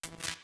SFX_Static_Electricity_Single_05.wav